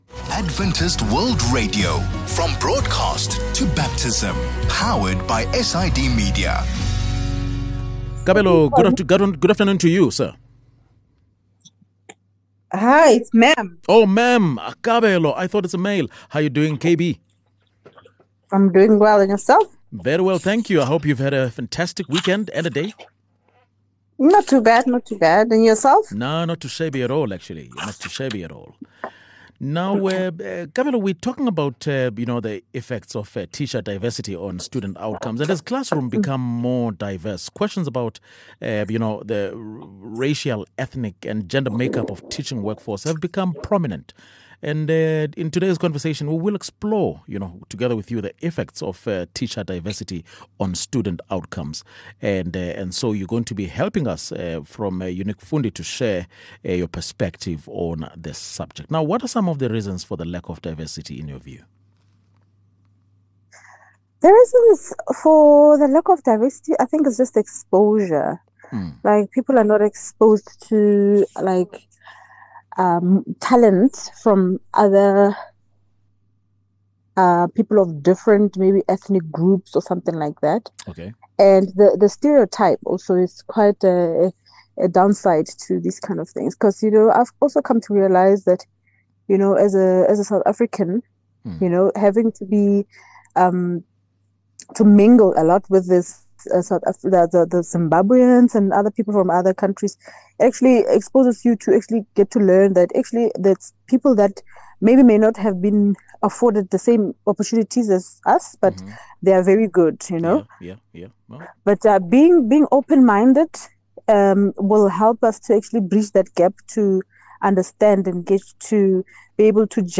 Intro: As classrooms become more diverse, questions about the racial, ethnic, and gender makeup of the teaching workforce have become prominent. In this conversation, we will explore the effects of teacher diversity on student outcomes.